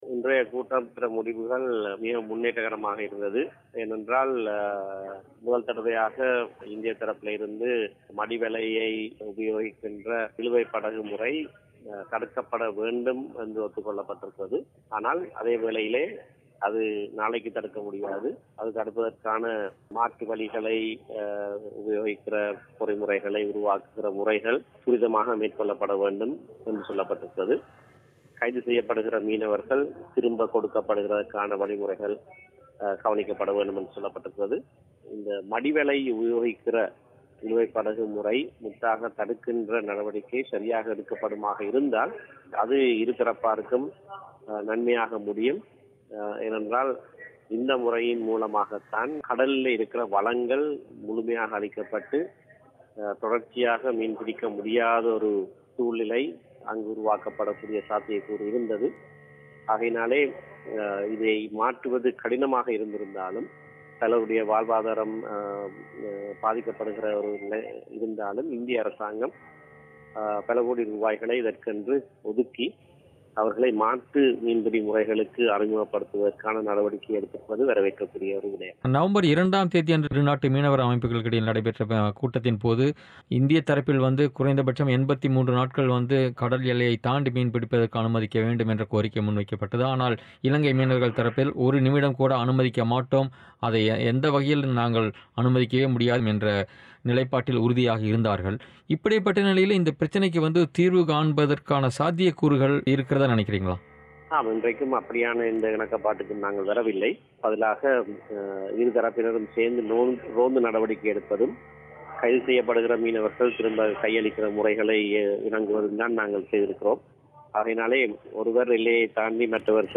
இலங்கை - இந்திய மீனவர் பிரச்சனைக்குத் தீர்வு காண கூட்டு நடவடிக்கைக்குழு அமைக்கப்பட்டிருப்பது பலன் தருமா? புதுடெல்லியில் சனிக்கிழமை நடைபெற்ற இரு நாட்டு அமைச்சர்கள் நிலையிலான பேச்சுவார்த்தையில் பங்கேற்ற தமிழ் தேசியக் கூட்டமைப்பின் நாடாளுமன்ற உறுப்பினர் எம்.ஏ. சுமந்திரன் பிபிசி தமிழோசைக்கு அளித்த பேட்டி.